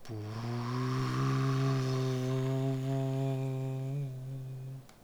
snd_spark.wav